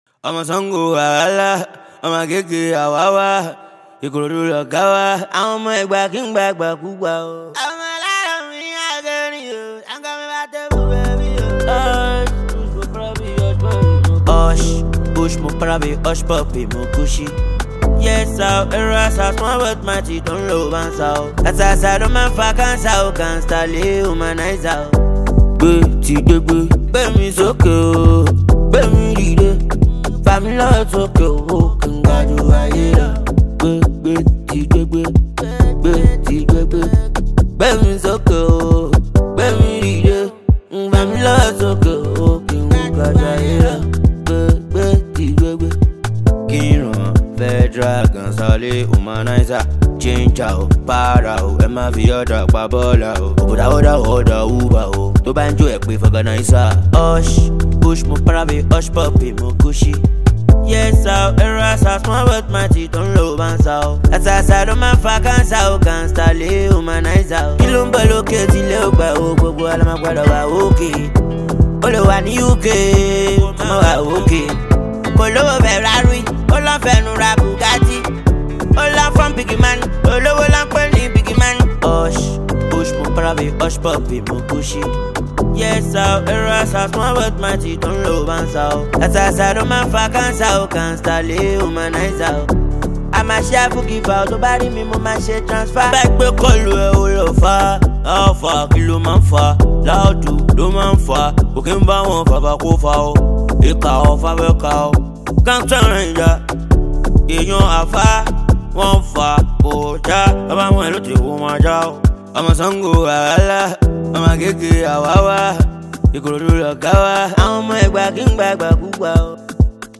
Nigerian Street-hop singer